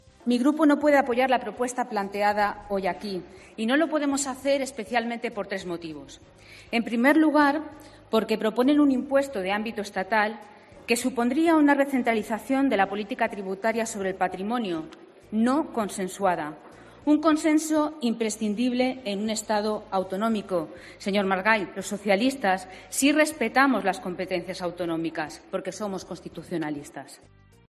Patricia Blanquer, diputada socialista, sobre el impuesto a los ricos